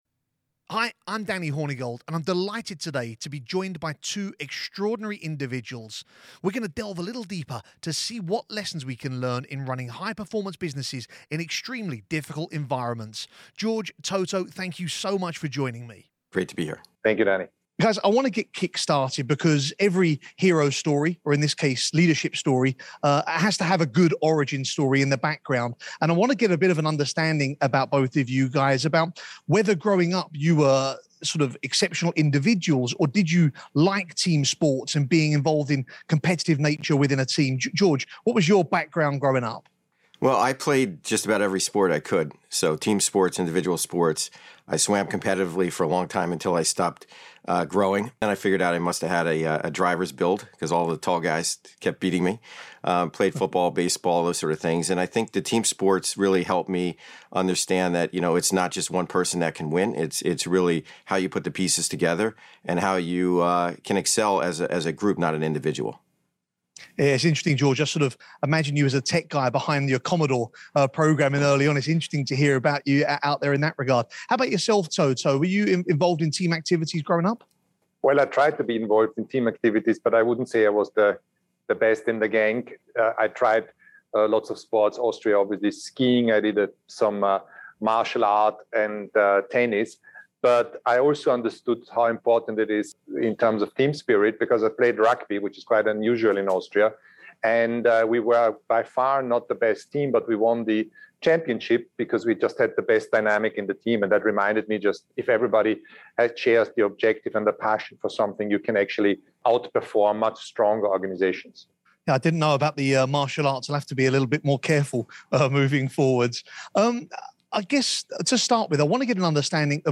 A Formula For Success - 2: CrowdStrike co-founder and CEO George Kurtz in conversation with CEO, Team Principal and co-owner of the Mercedes-AMG Petronas F1 Team, Toto Wolff – Part 1